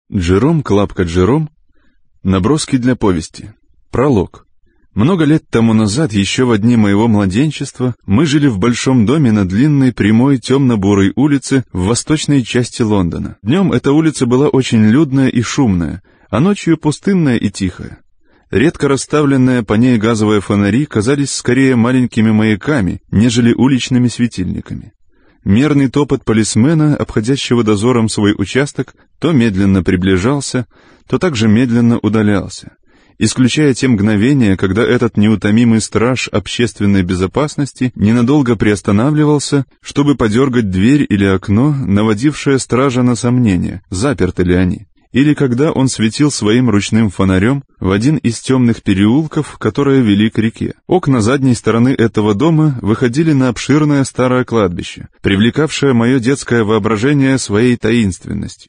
Аудиокнига Наброски для повести | Библиотека аудиокниг